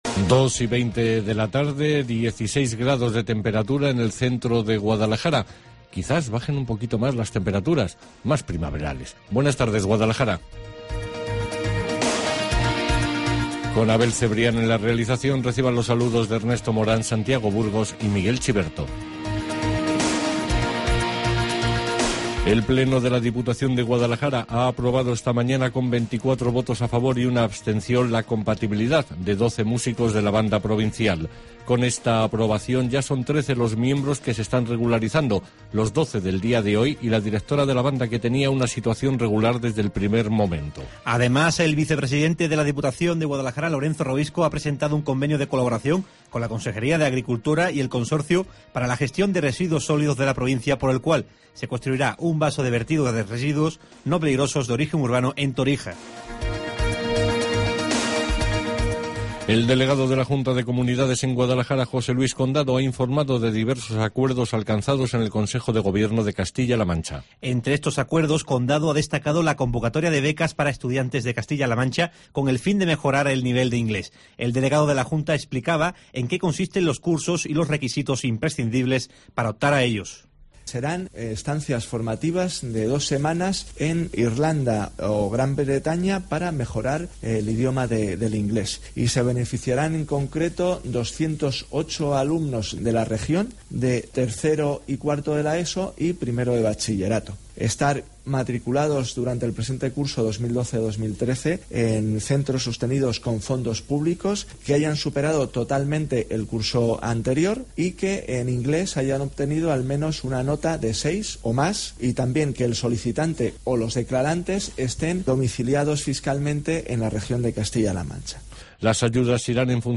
Informativo Guadalajara 19 de abril